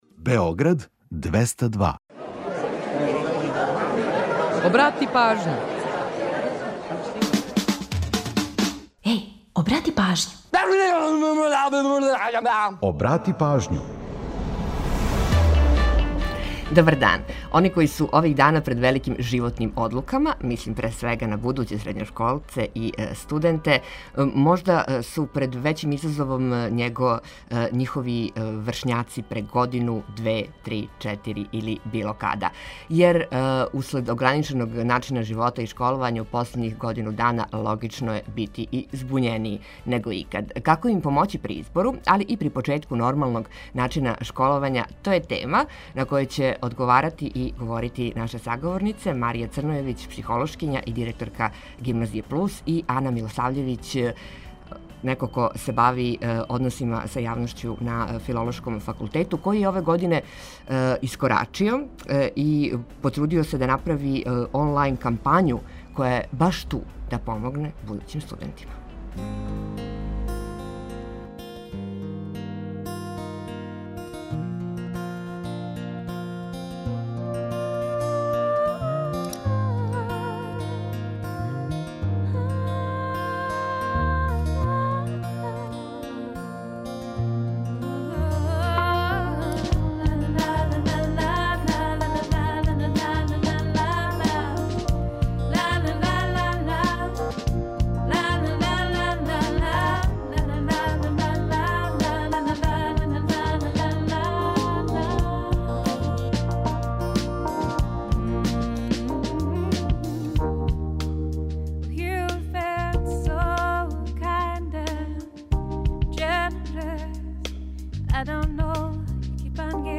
У наставку емисије, подсетићемо вас на важне догађаје у поп рок историји који су догодили на данашњи дан. Ту је и пола сата резервисаних за домаћицу, музику из Србије и региона, прича о једној песми и низ актуелних занимљивости и важних информација.